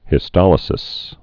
(hĭ-stŏlĭ-sĭs)